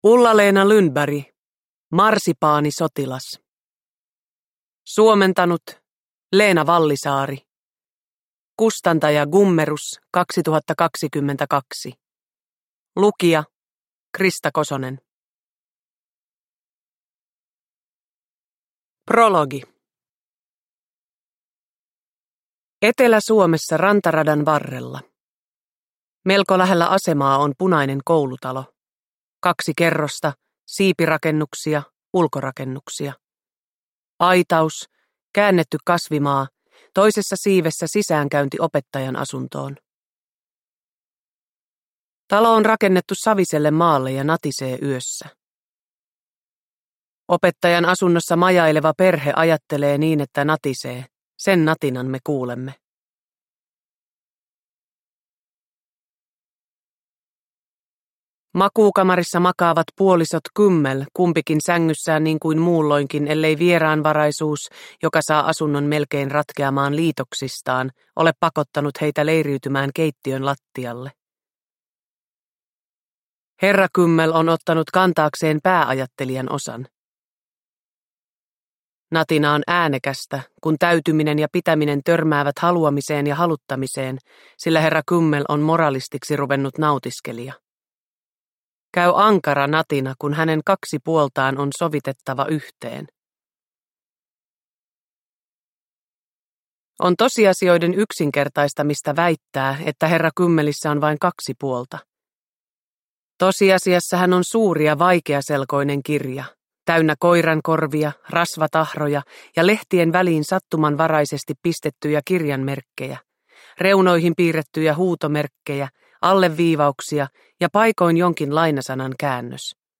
Marsipaanisotilas – Ljudbok – Laddas ner
Uppläsare: Krista Kosonen